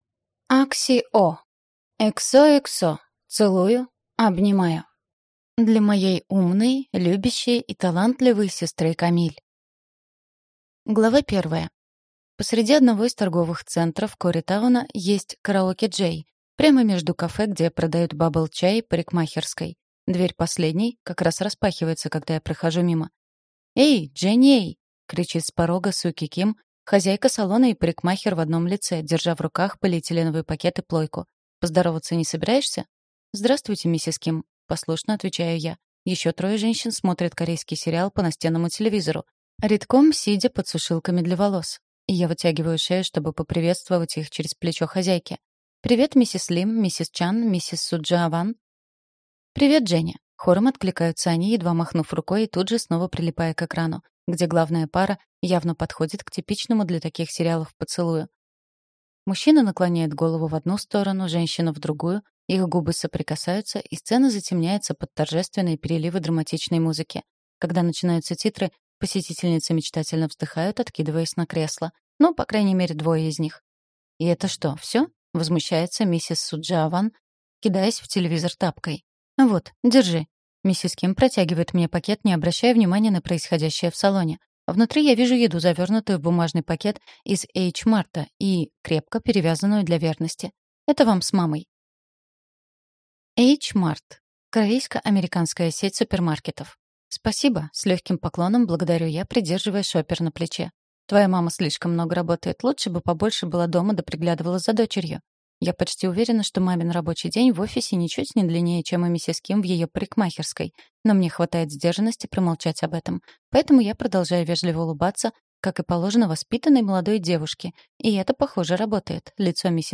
Аудиокнига ХОХО. Целую. Обнимаю | Библиотека аудиокниг